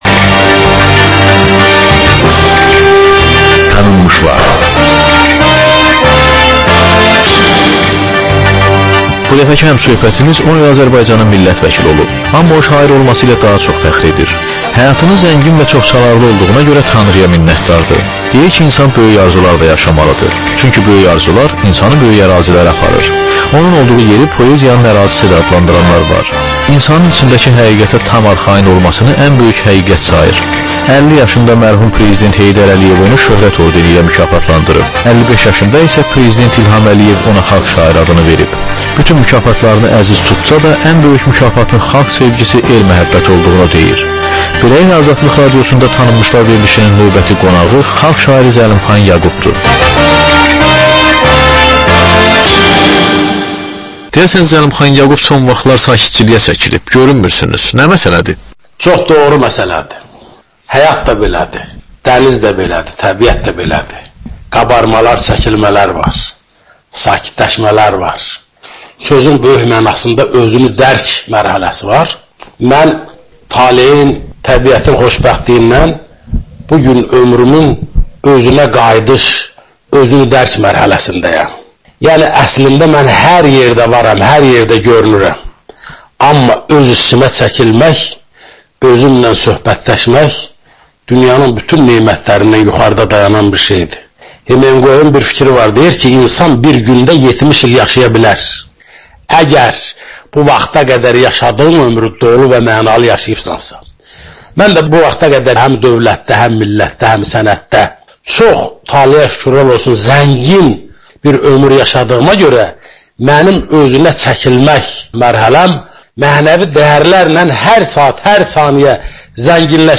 Ölkənin tanınmış simalarıyla söhbət.